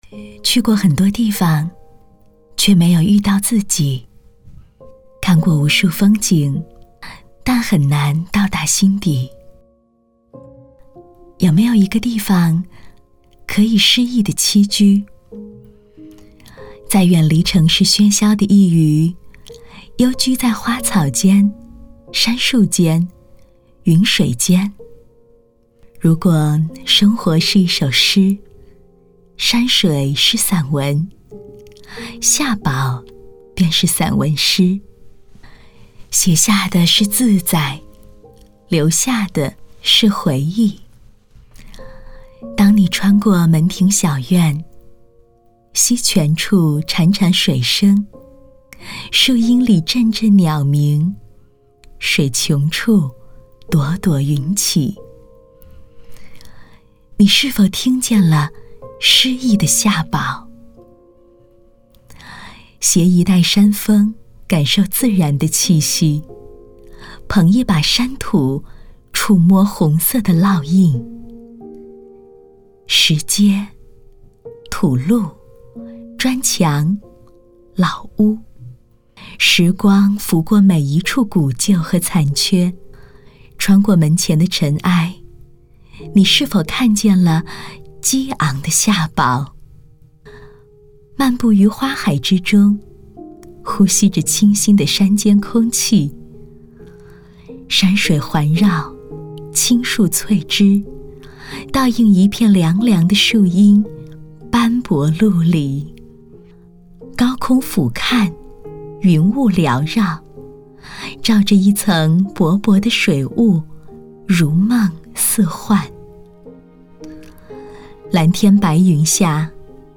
国语女声
亲切甜美